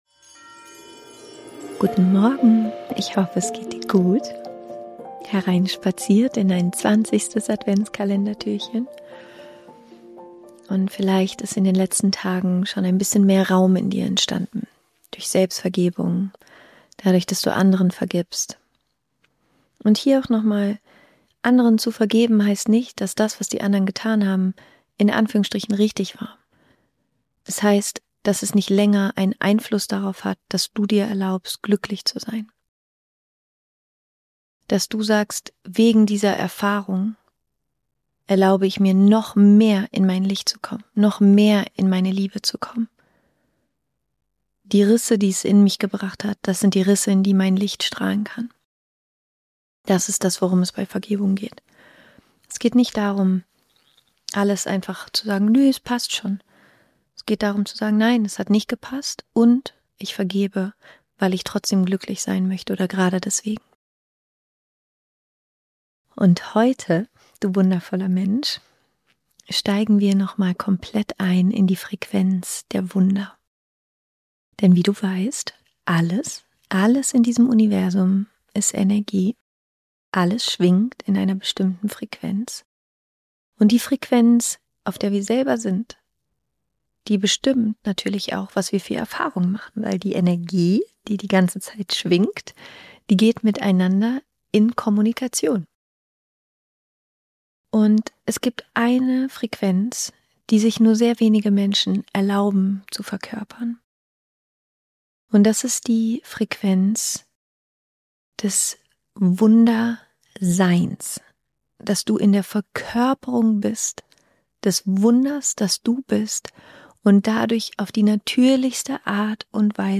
Heute geht es um die Frequenz des reinen Lichtes – um den Moment, in dem du nicht mehr zwischen dir und dem Göttlichen stehst. In einer kraftvollen Mini-Meditation wirst du deinen Kanal öffnen, durch den Wunder natürlich fließen können.